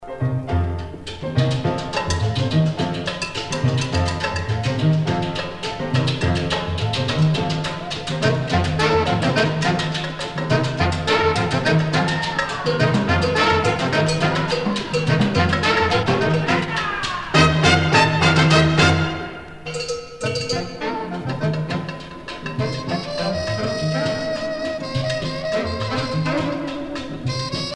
danse : danzón
Pièce musicale éditée